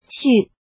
怎么读
xu